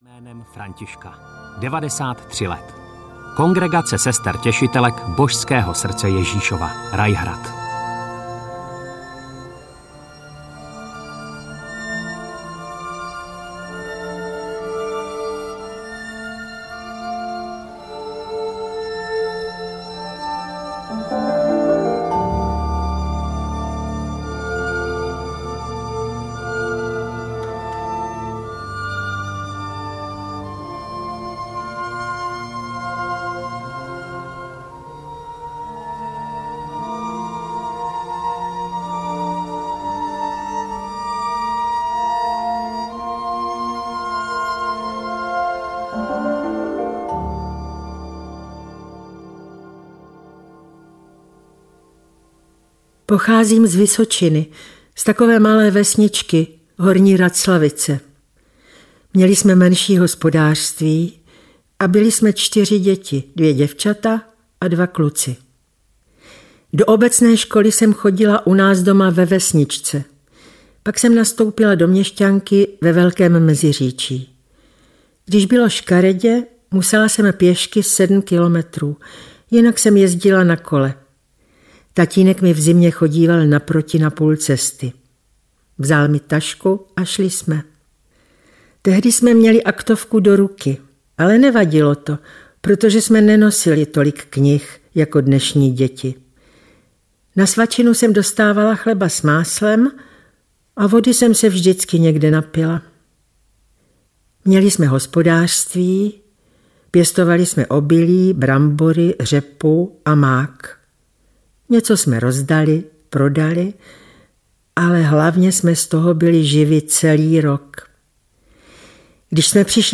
Sestry audiokniha
Ukázka z knihy